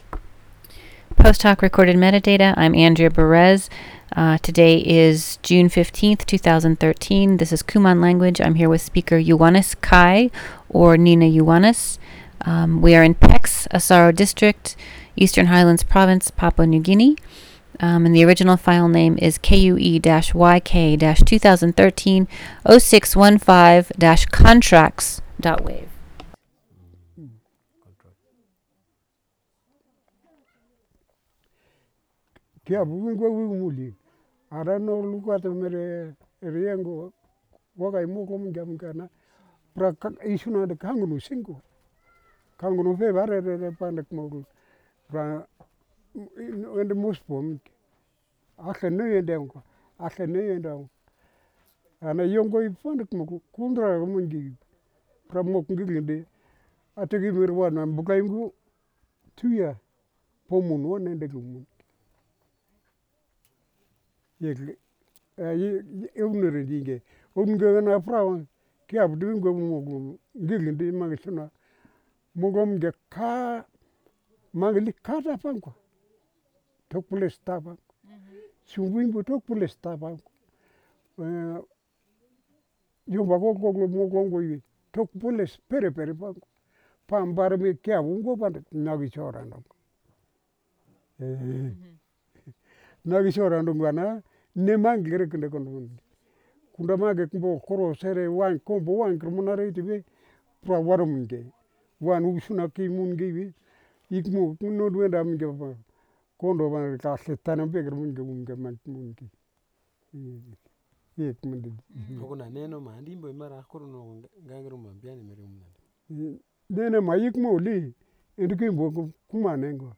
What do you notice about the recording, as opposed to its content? dc.description.regionPex Village, Asaro District, Eastern Highlands Province, Papua New Guinea dc.formatdigital wav file recorded at 44.1 kHz/16 bit on Zoom H4n solid state recorder with Countryman e6 headset microphone